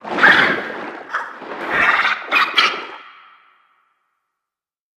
Sfx_creature_seamonkey_give_01.ogg